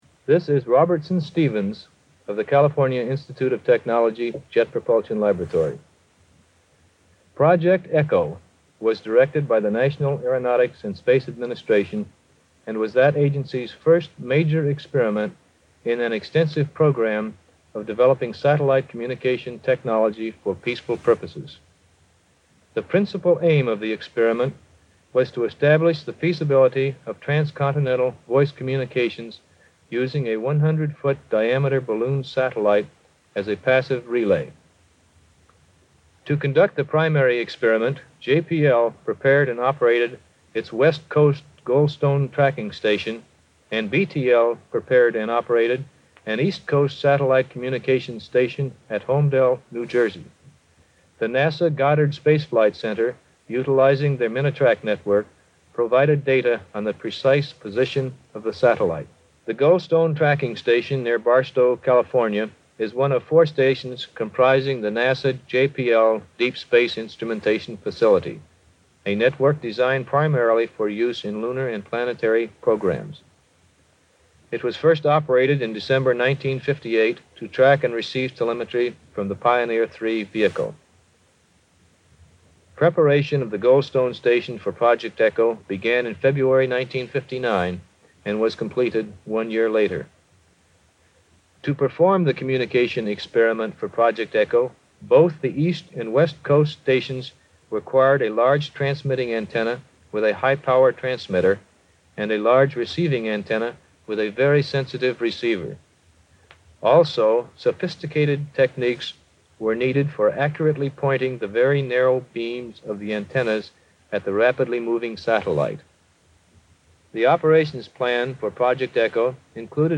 This vintage recording recently found in the NASA Jet Propulsion Laboratory audio archives comes from NASA's first successful communications satellite, Project Echo. The project involved bouncing radio signals off a 10-story-high, aluminum-coated balloon orbiting the Earth in 1960.
JPL sent and received signals through two of its 85-foot-diameter antennas at the West Coast Goldstone tracking station in the Mojave Desert.